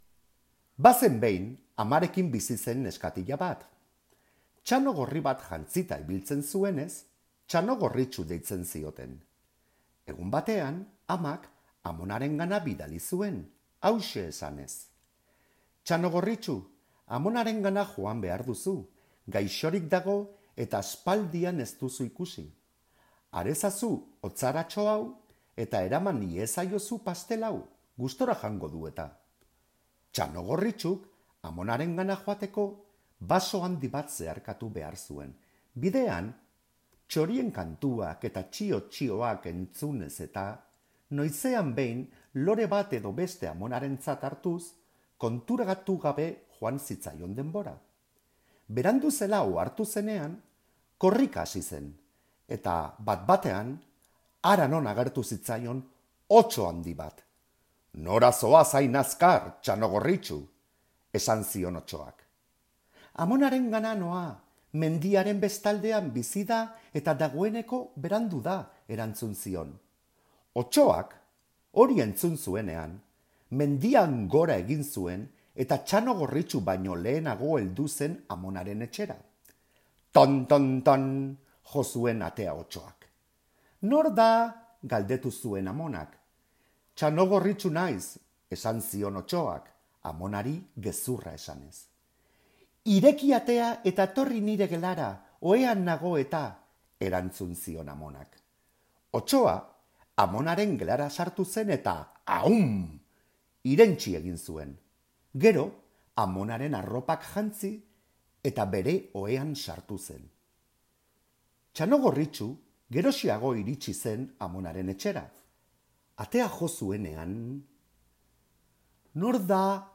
Oral History Item Type Metadata
Donosti-San Sebastian, Basque Country